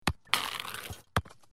Звук забитого гола